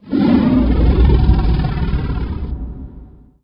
PixelPerfectionCE/assets/minecraft/sounds/mob/enderdragon/growl3.ogg at mc116
growl3.ogg